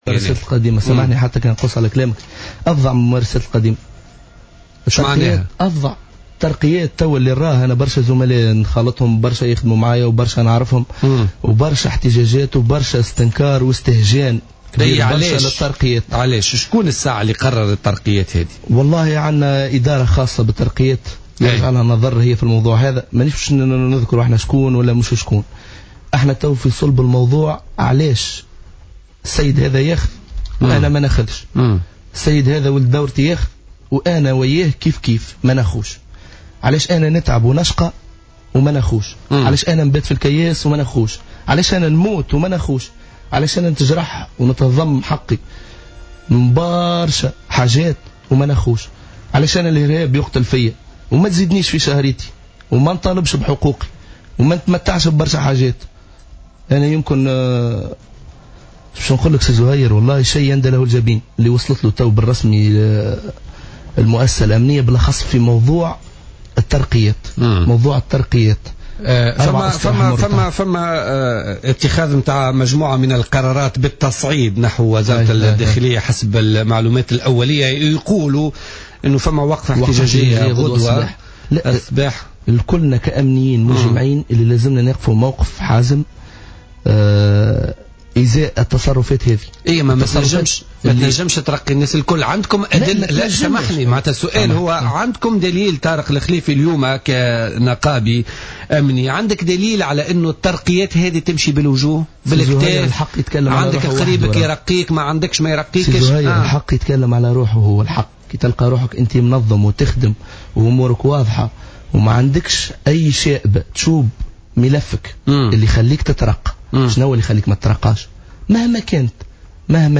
ضيف برنامج "بوليتيكا"